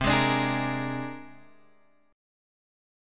C 7th Suspended 2nd Sharp 5th